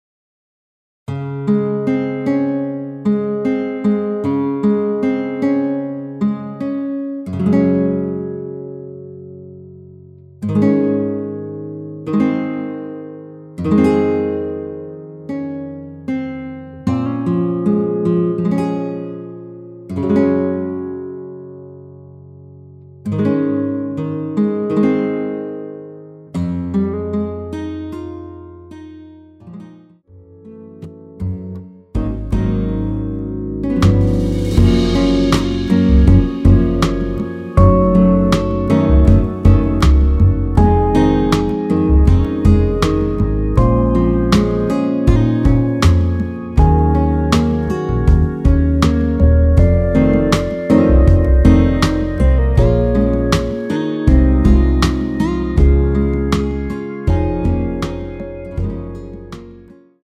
전주 없이 시작하는 곡이라 라이브 하기 좋게 전주 2마디 만들어 놓았습니다.(미리듣기 확인)
원키에서(-2)내린 MR입니다.
Db
앞부분30초, 뒷부분30초씩 편집해서 올려 드리고 있습니다.